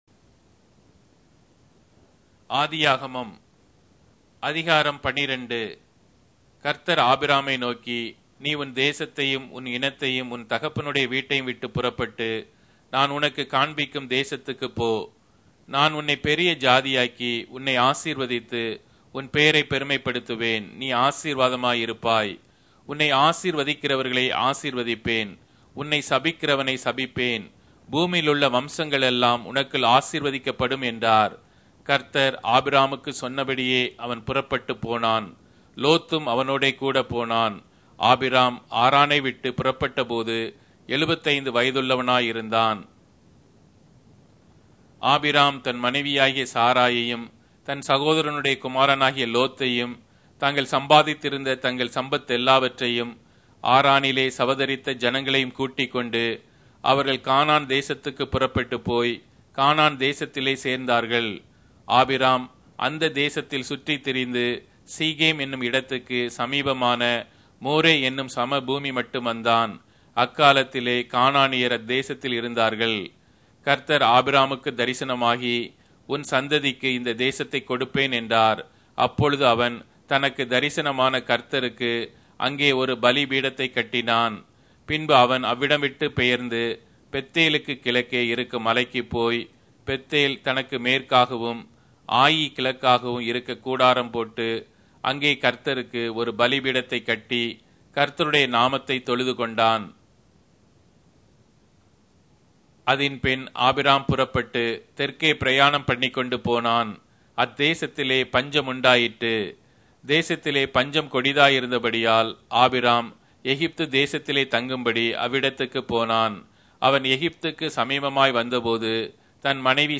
Tamil Audio Bible - Genesis 25 in Nlt bible version